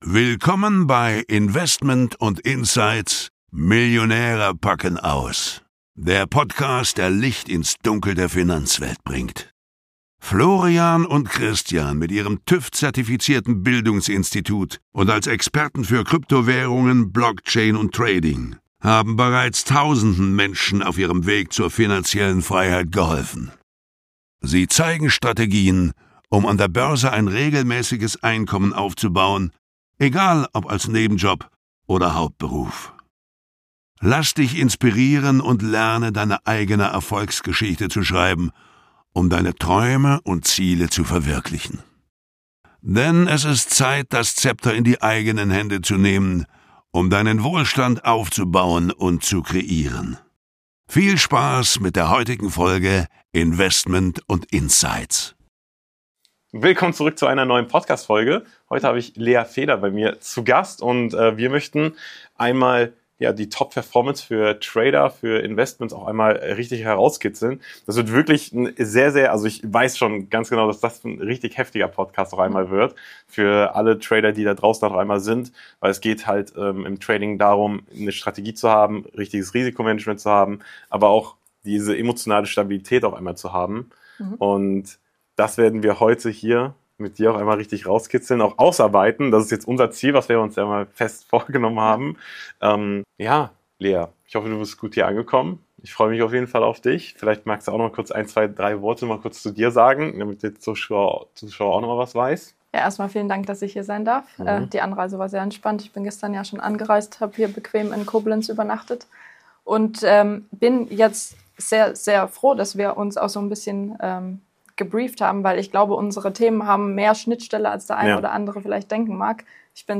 Erfahre, warum persönliches Wachstum der Schlüssel zu wahrem Erfolg ist und wie du mit Klarheit und innerer Stärke finanzielle Freiheit erreichst. Ein tiefgehendes Gespräch über Angst, Visionen und die Kraft, neu anzufangen – offen, ehrlich & motivierend!